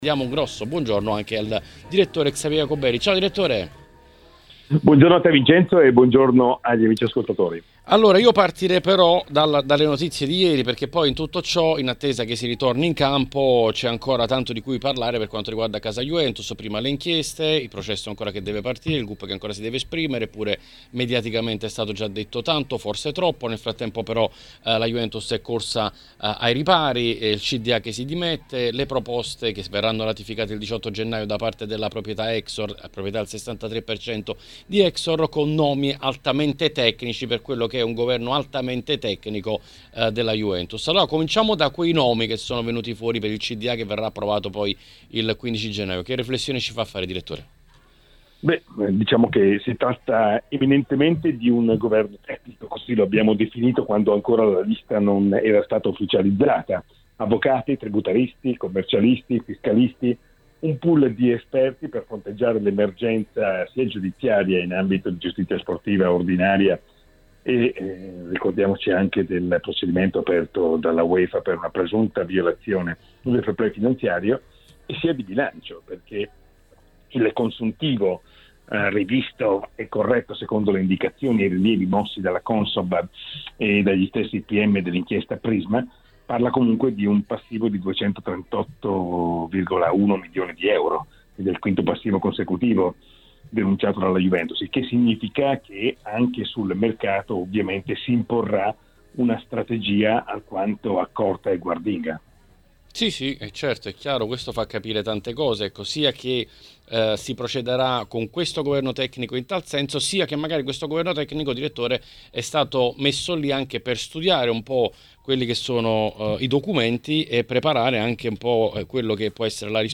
Durante l'appuntamento odierno con L'Editoriale è intervenuto sulle frequenze di TMW Radio Xavier Jacobelli.